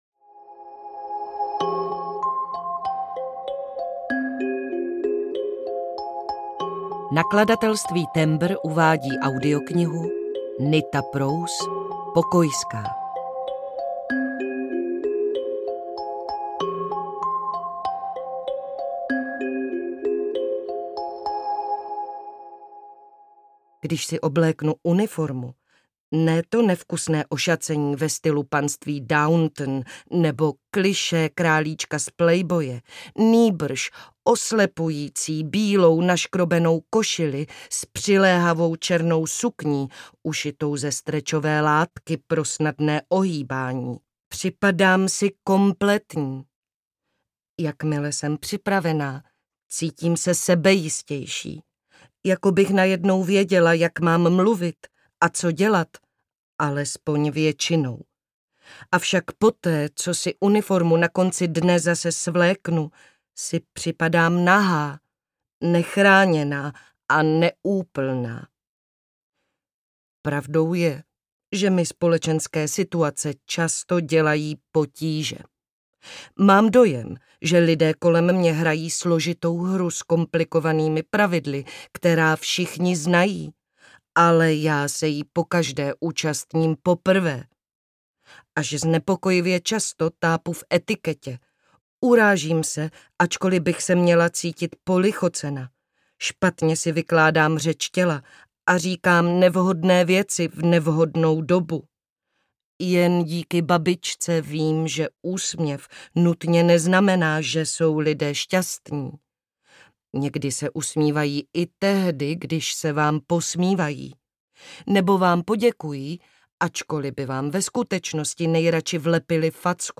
Pokojská audiokniha
Ukázka z knihy